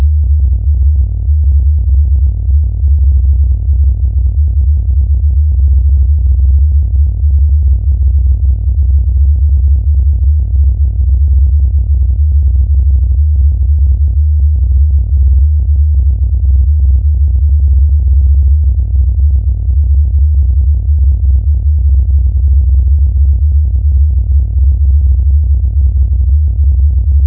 You will notice a "wobbling" characteristic because the phase of the 75-Hz carrier frequency is shifted roughly 88.2 degrees frequently to define a binary code called an M-Sequence. Each bit of the code is two cycles of the carrier frequency. The code usually has 1023 bits and is repeated several times to make one long transmission.